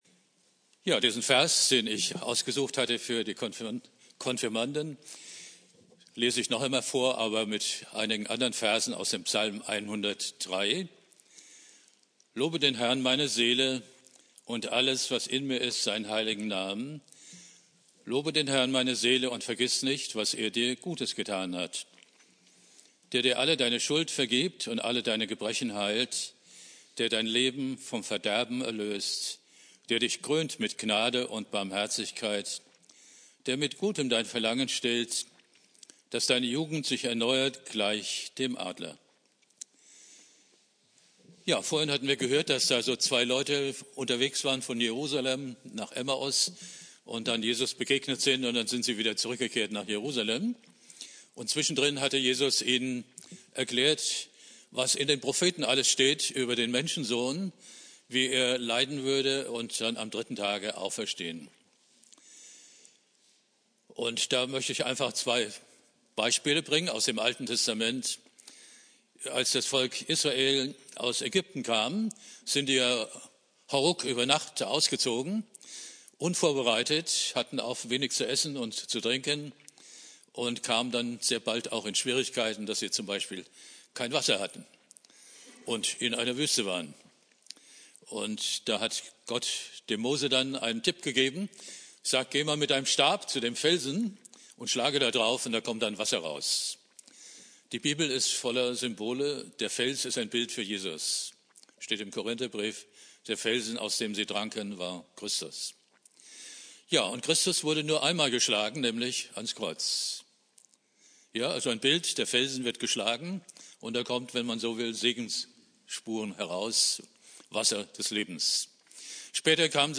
Ostermontag Prediger